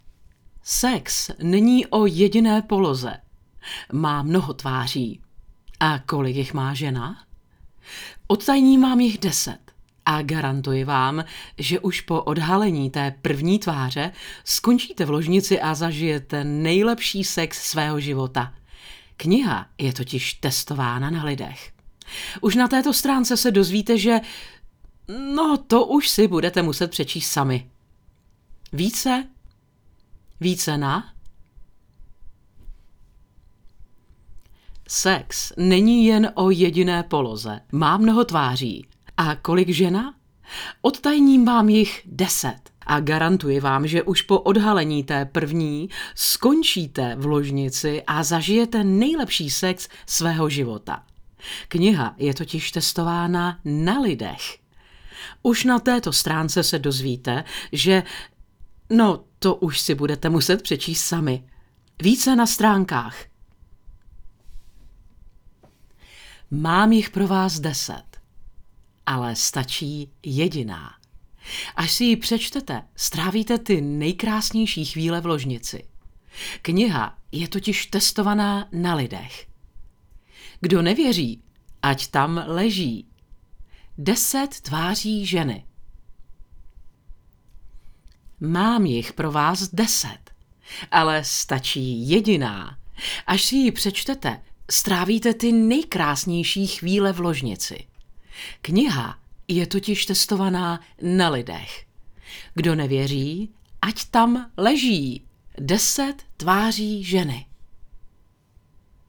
Umím: Voiceover, Tvorba a úprava audia
Ženský hlas - voiceover/dabing